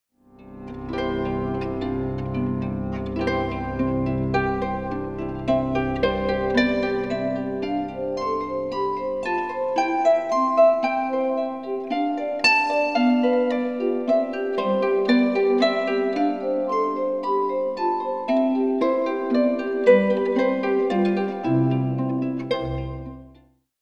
Featuring the harp, piano & guitar
Recorded at Healesville Sanctuary